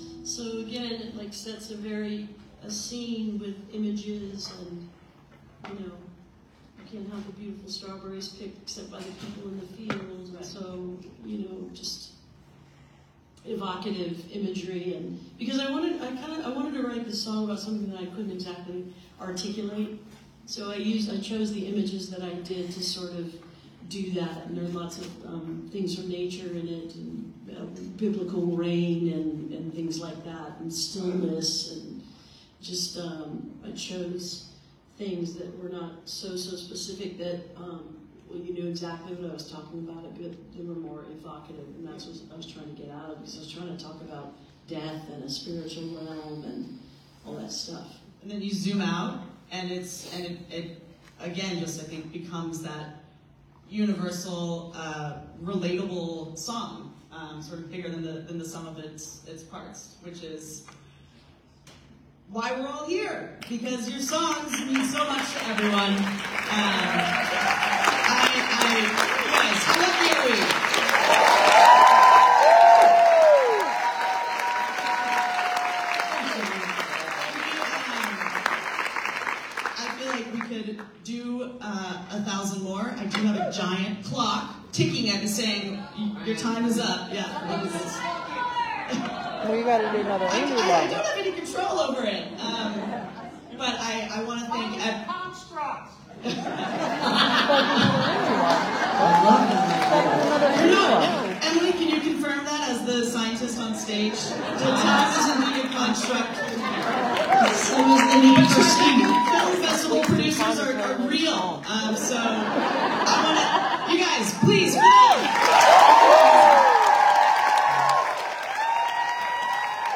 12. conversation (2:52)